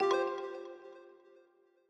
Longhorn Ten Alfa - User Account Control.wav